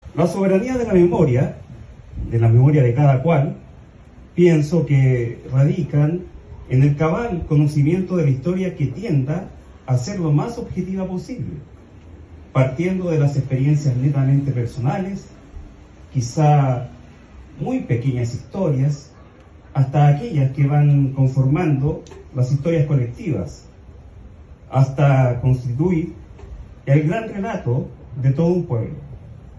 Inauguración de Cafetería Rapelli